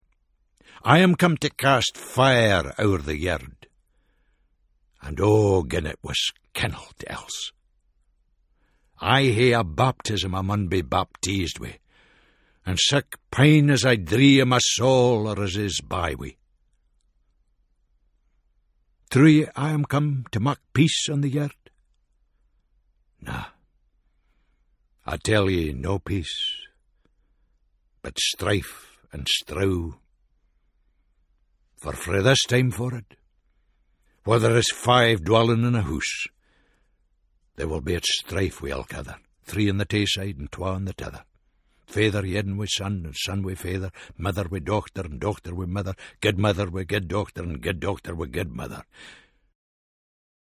In the recordings of the Scots New Testament, Tom Fleming brings the well known stories of the gospels to life in a distinct Scots voice.